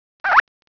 A strange frrt/squeek type noise